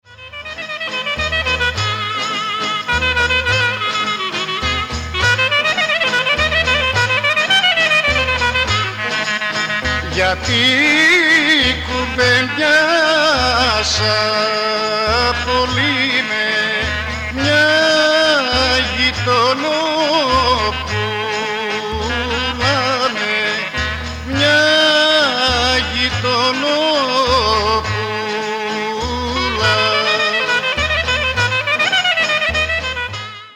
Tsamikos
lavouto
guitar